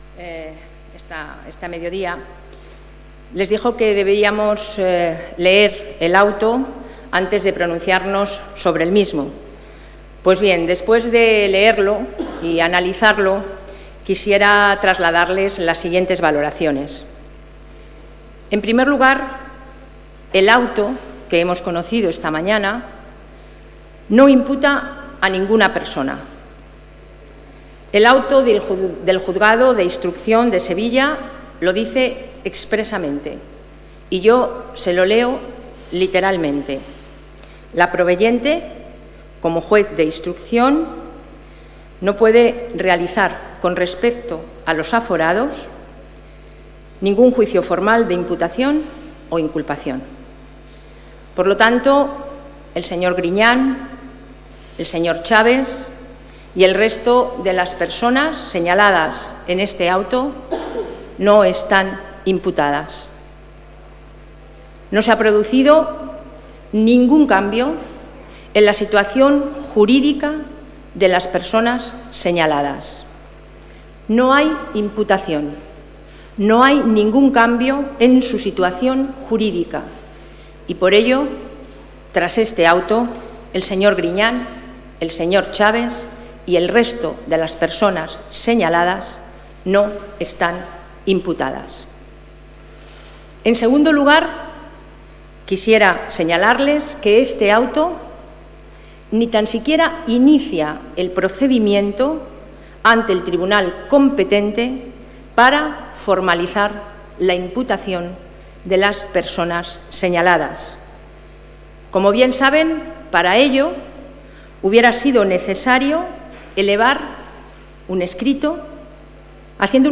Declaraciones de Soraya Rodríguez en defensa de la honestidad de Chaves y Griñán 10/09/2013